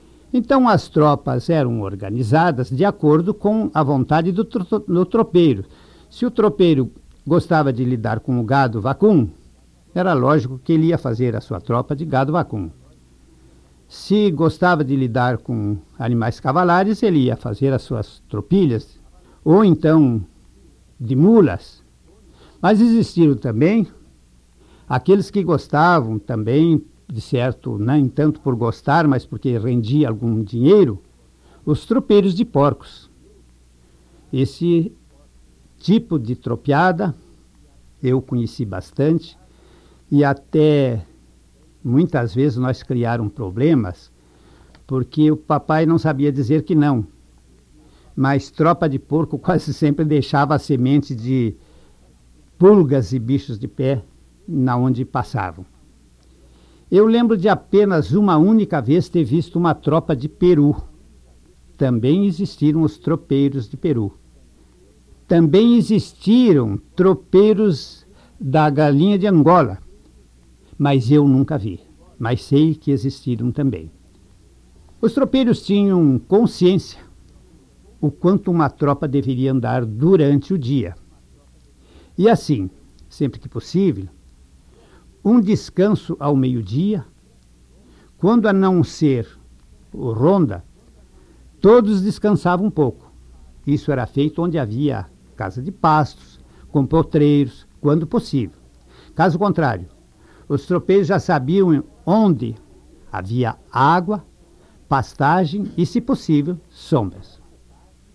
Unidade Banco de Memória Oral